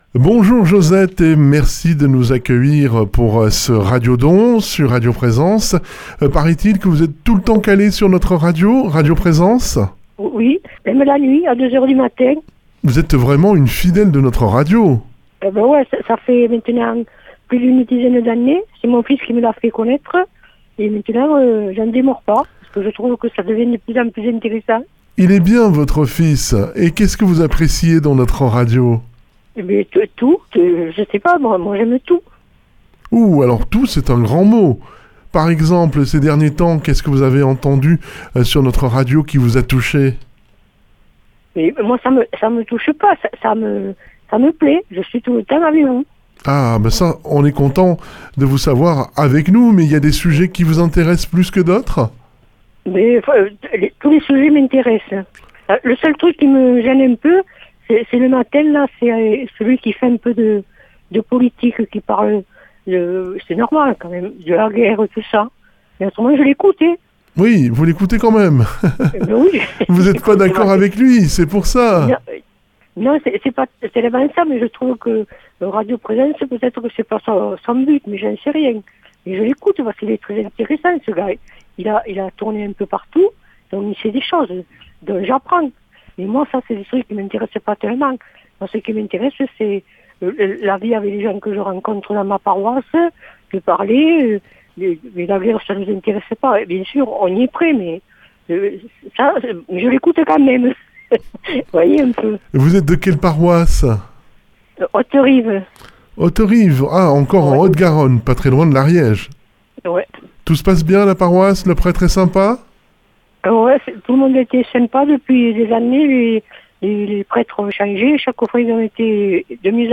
Un témoignage chaleureux pour soutenir le Radio Don 2025.